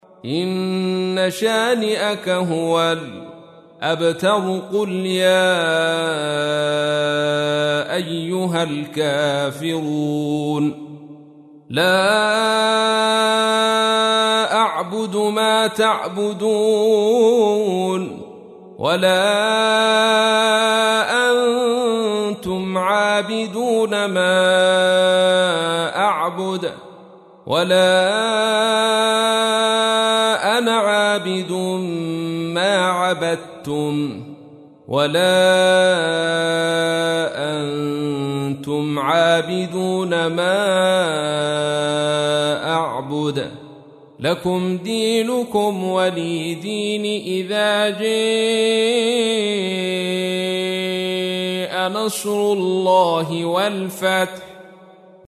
تحميل : 109. سورة الكافرون / القارئ عبد الرشيد صوفي / القرآن الكريم / موقع يا حسين